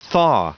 Prononciation du mot thaw en anglais (fichier audio)
Prononciation du mot : thaw